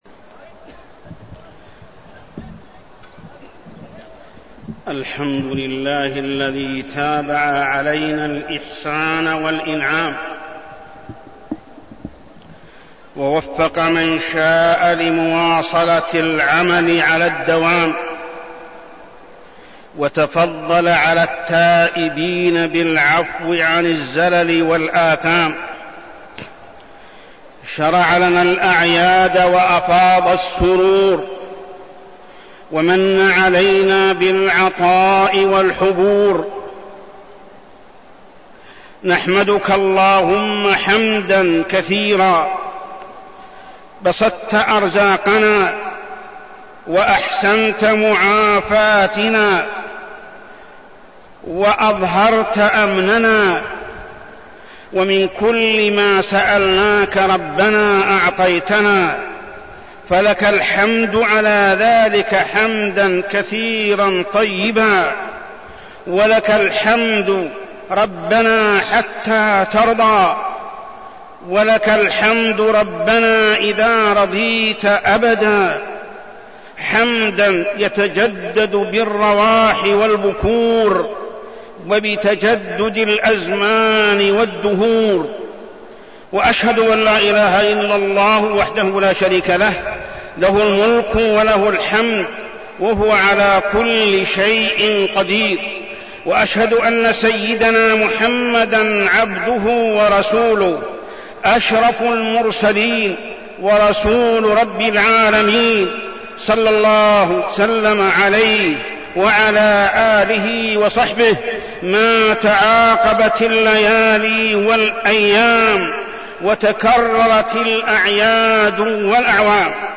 خطبة عيد الفطر-مناصرة المضطهدين والإهتمام بهم
تاريخ النشر ١ شوال ١٤١٨ هـ المكان: المسجد الحرام الشيخ: محمد بن عبد الله السبيل محمد بن عبد الله السبيل خطبة عيد الفطر-مناصرة المضطهدين والإهتمام بهم